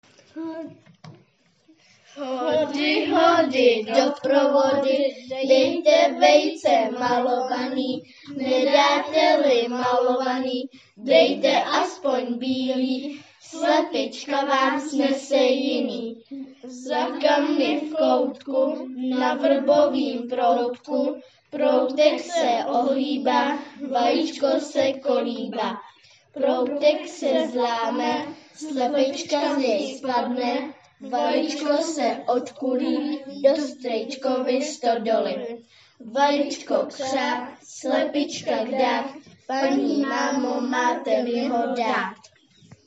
Velikonoční koleda v podání dětí ze ZŠ